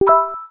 Click Sound Effects MP3 Download Free - Quick Sounds